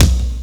BD 06.wav